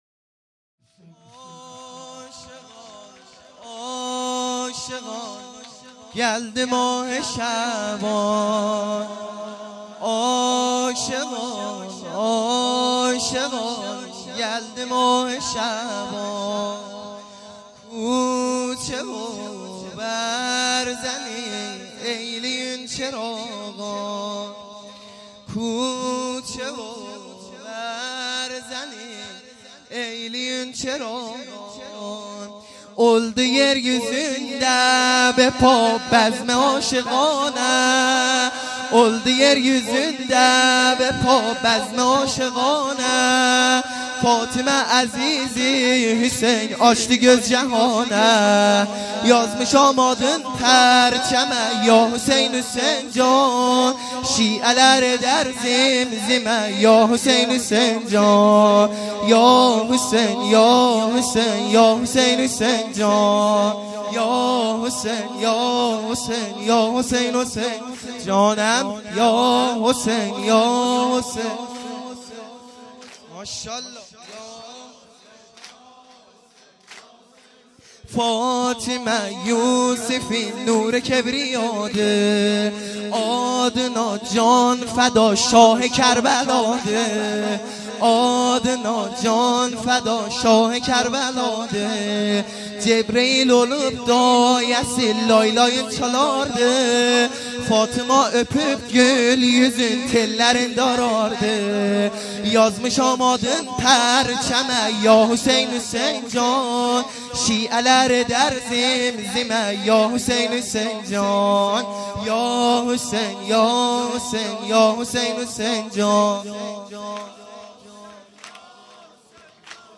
سرود | عاشقان گلدی ماه شعبان